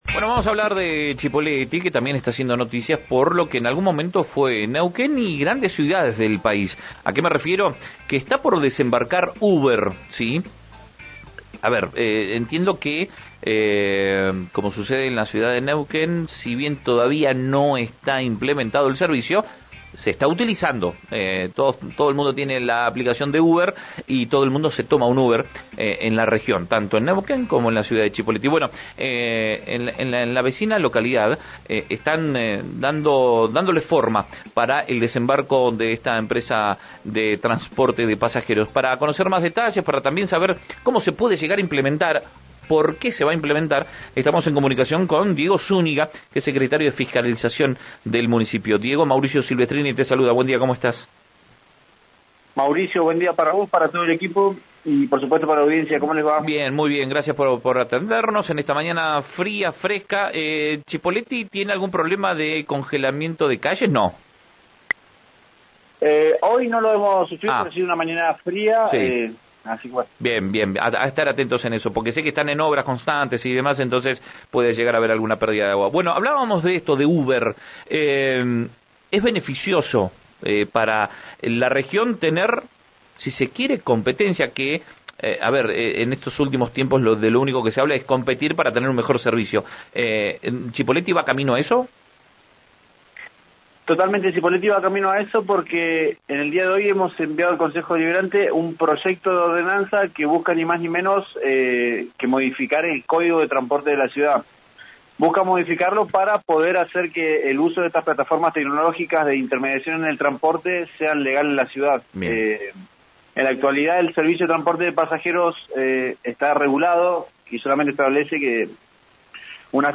Escuchá a Diego Zúñiga, secretario de Fiscalización de Cipolletti, en RN Radio